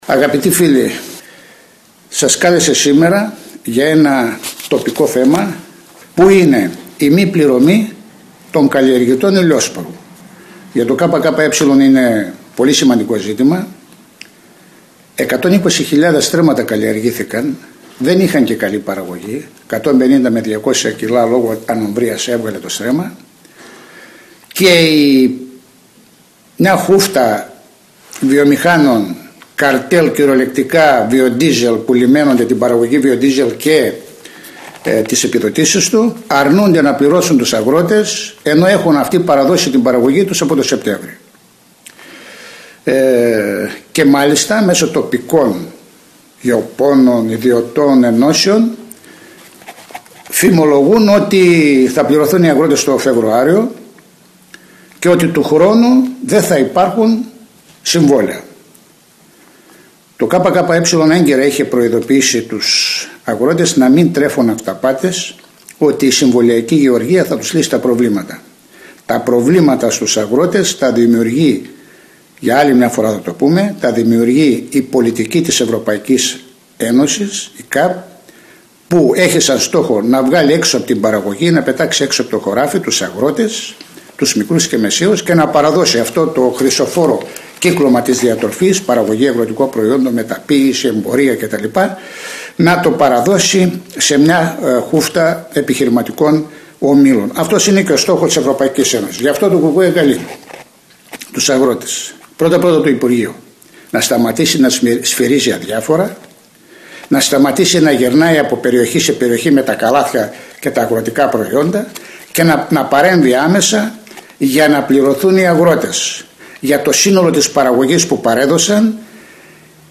σε σχετική συνέντευξη τύπου για το ζήτημα των απλήρωτων αγροτών